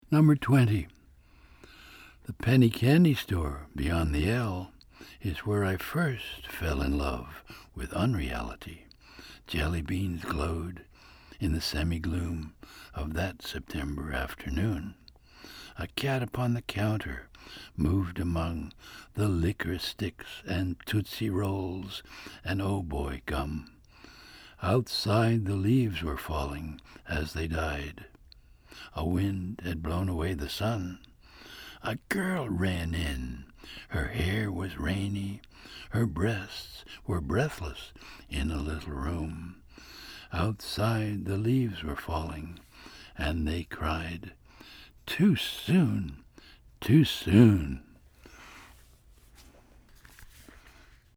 Lawrence Ferlinghetti reads a poem from A Coney Island of the Mind, published by New Directions in 1958.